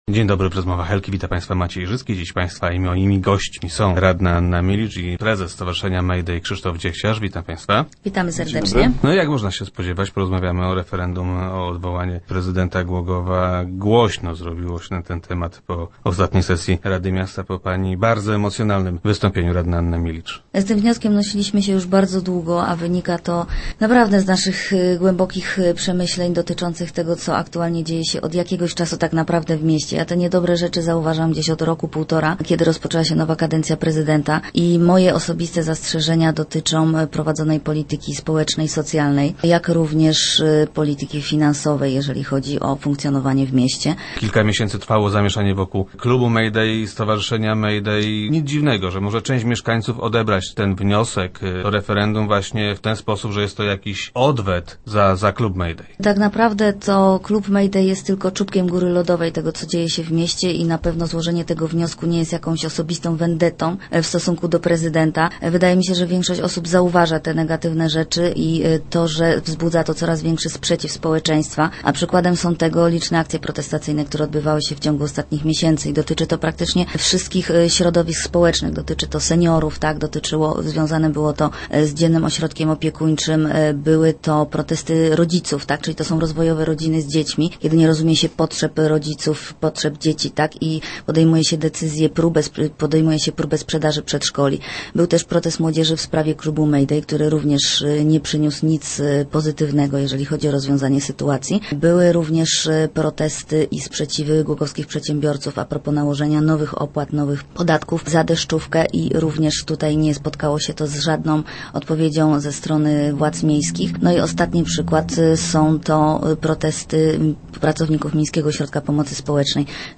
Start arrow Rozmowy Elki arrow Milicz: To nie jest wendetta